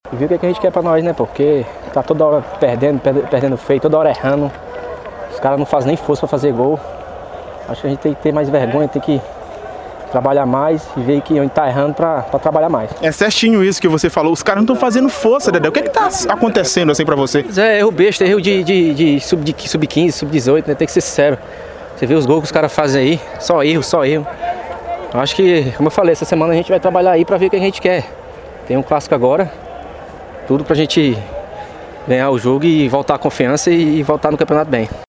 Em tom de sinceridade, os jogadores fizeram uma avaliação sobre o momento da equipe e todas as atuações abaixo da crítica.